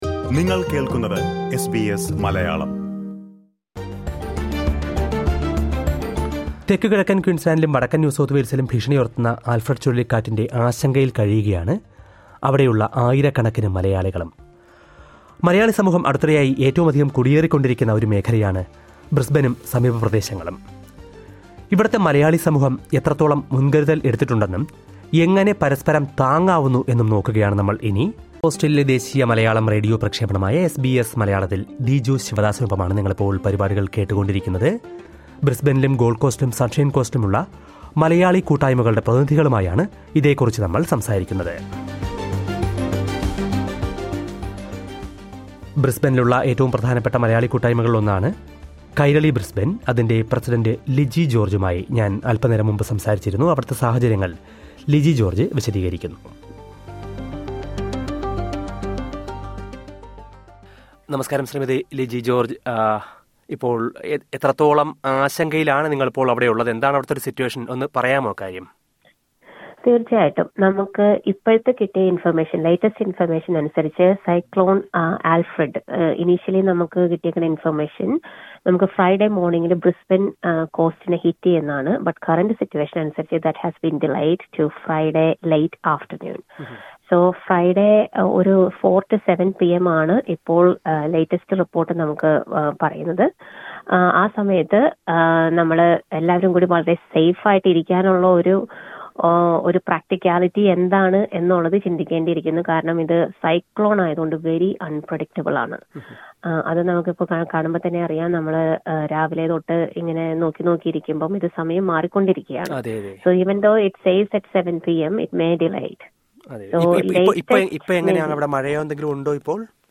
ബ്രിസ്‌ബൈനിലും, സമീപത്തെ ഗോള്‍ഡ് കോസ്റ്റ്, സണ്‍ഷൈന്‍ കോസ്റ്റ് എന്നിവിടങ്ങളിലുമുള്ള മലയാളി സമൂഹത്തിന്റെ ആശങ്കകളെയും, മുന്‍കരുതലുകളെയും കുറിച്ച് ഇവിടത്തെ കൂട്ടായ്മകളുടെ പ്രതിനിധികള്‍ വിശദീകരിക്കുന്നത് കേള്‍ക്കാം.